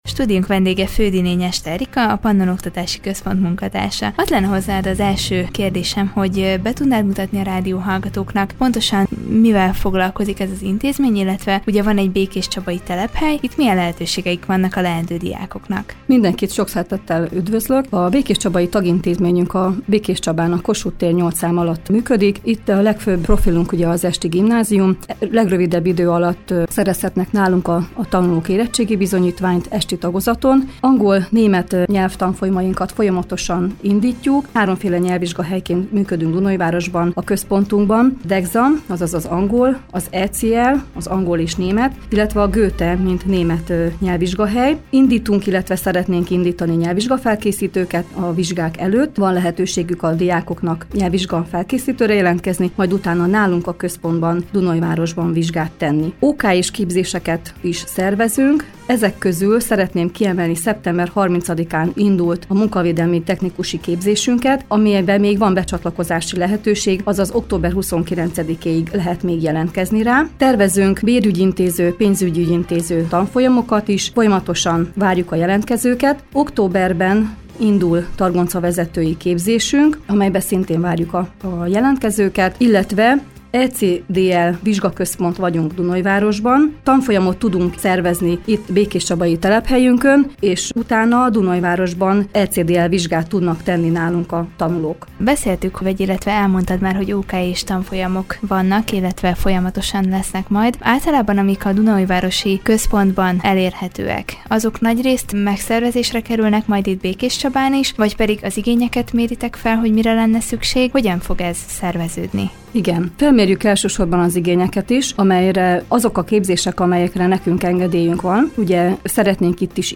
Vele beszélgetett tudósítónk a diákok lehetőségeiről az oktatási központnál valamint az OKJ-s tanfolyamokról és azok szervezéséről.